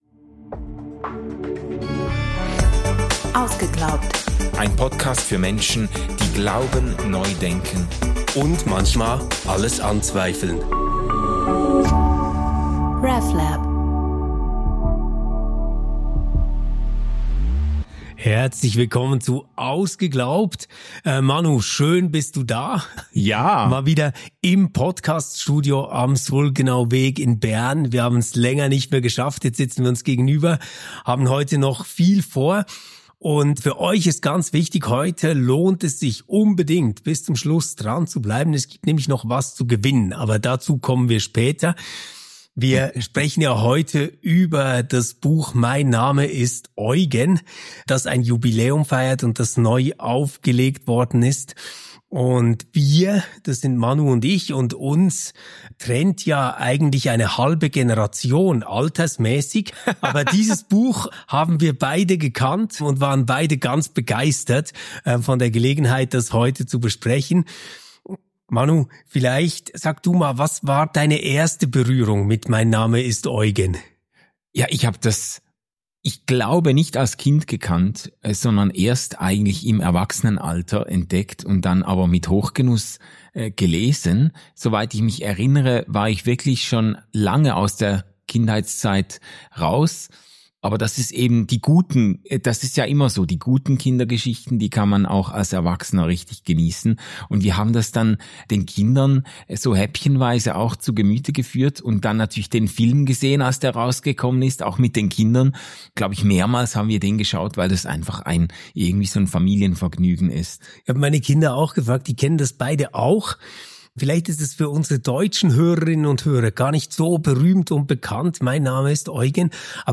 Die Folge musste auf einem Notfallsystem entstehen.